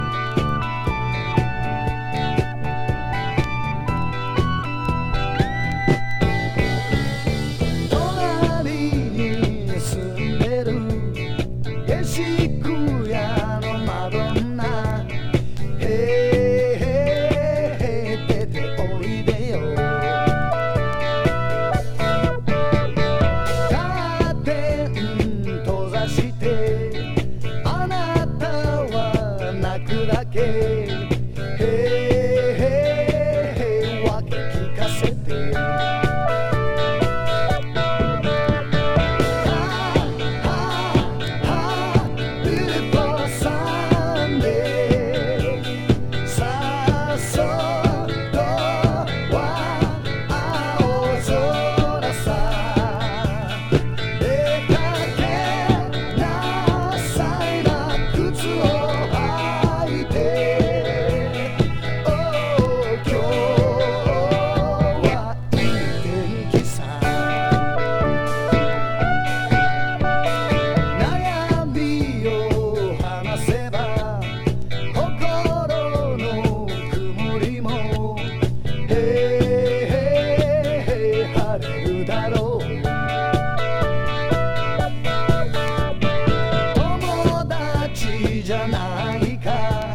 BLACK
ほか、ファンキーなディスコアレンジ・カバーをA面に、オリジナル曲をB面に収録してます。